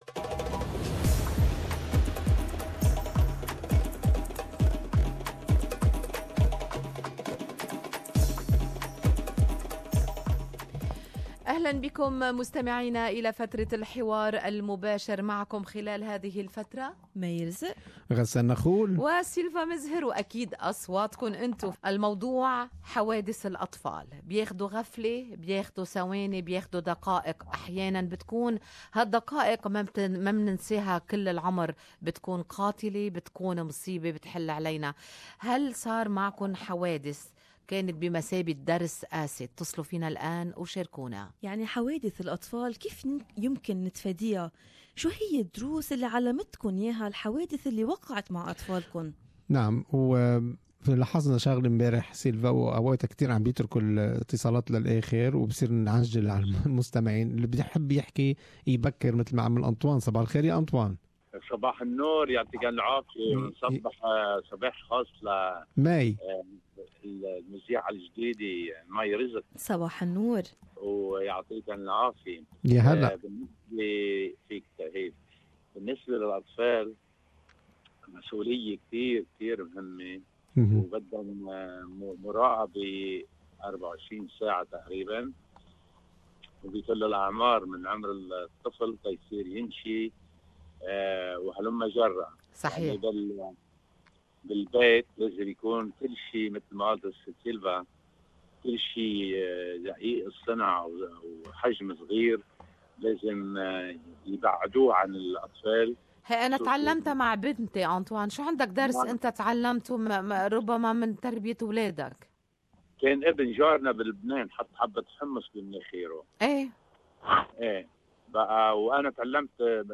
From avoiding leaving your children unsupervised and paying attention to small play objects to marking detergents with clear no drinking signs, our listeners share with us live tips that will help avoid children accidents!